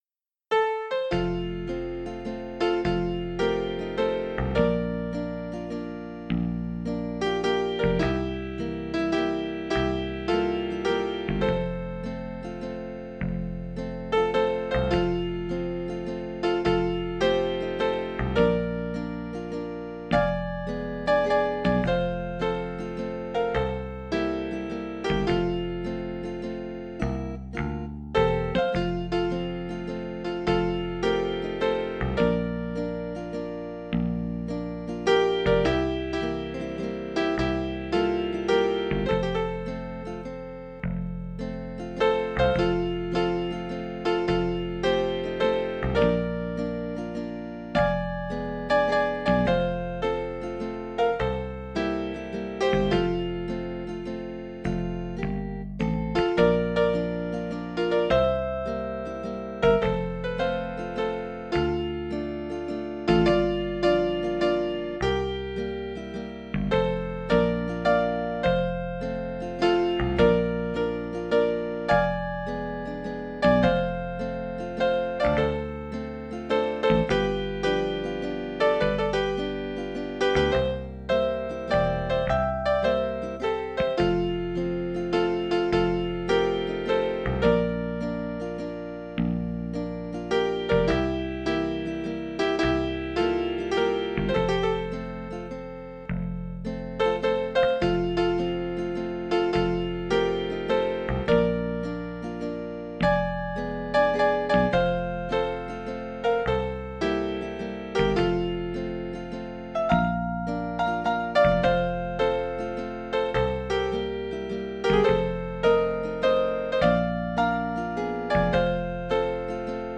I have created a ( MIDI file )  for the sheet music you will see below and it can give you an idea how the song is played.
.MP3  Or  .Ogg  Of  The  MIDI  File